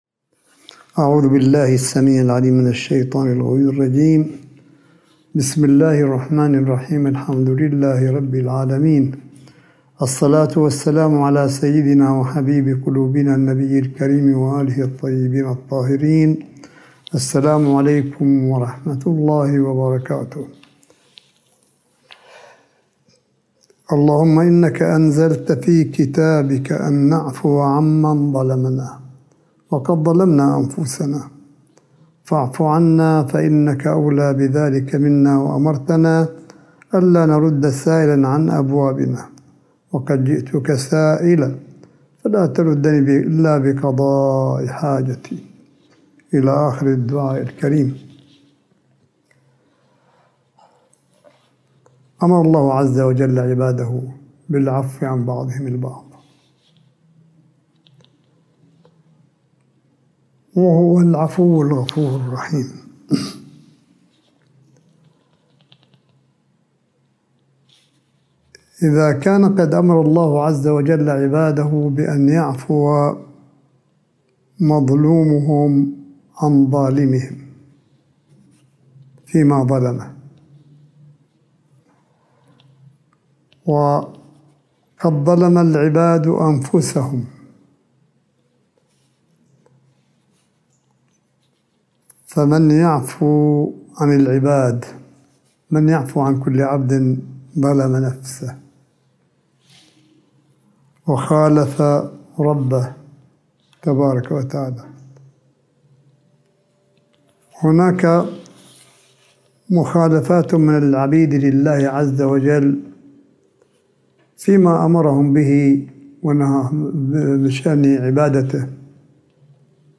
ملف صوتي للحديث الرمضاني (31) لسماحة آية الله الشيخ عيسى أحمد قاسم حفظه الله – 14 مايو 2021م